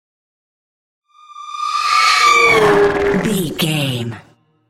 Horror whoosh large
Sound Effects
In-crescendo
Atonal
scary
tension
ominous
eerie